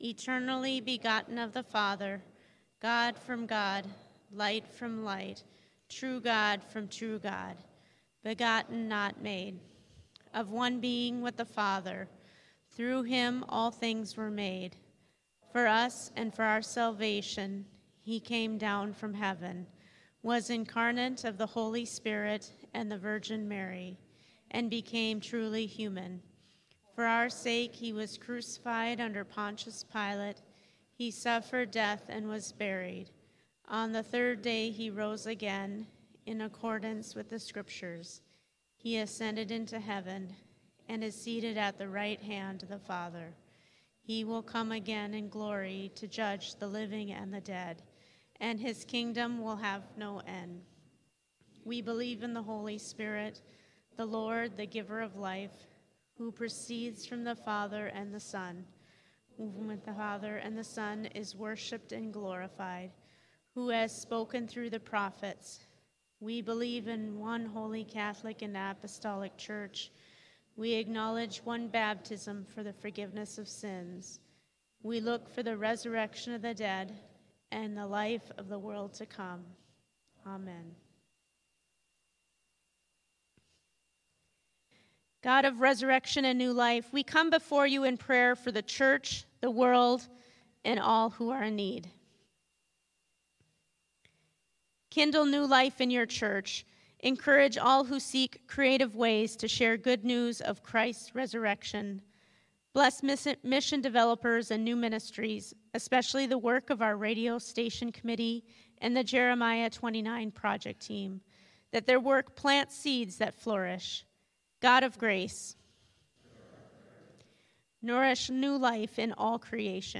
Sermons | Shalom Lutheran Church
April 20, 2025: Easter Sunday C